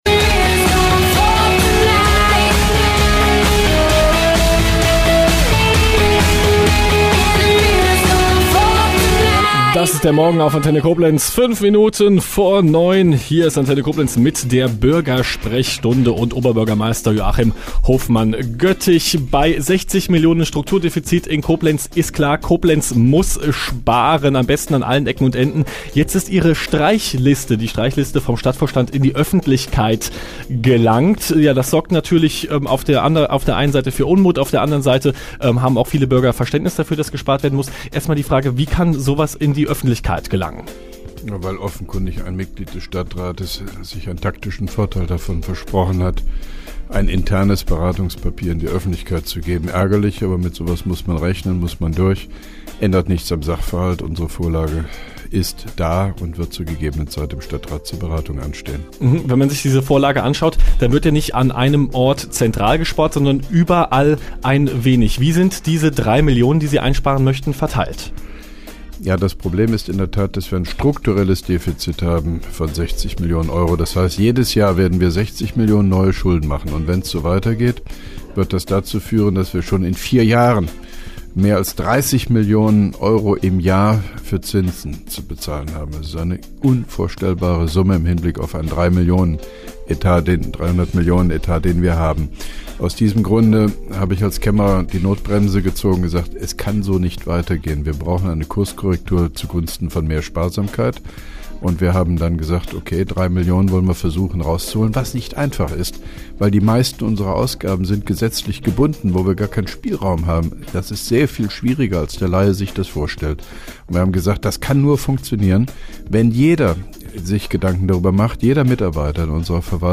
(3) Koblenzer Radio-Bürgersprechstunde mit OB Hofmann-Göttig 15.03.2011